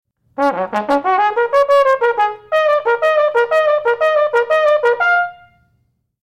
to offer some recordings of the audition technical etudes: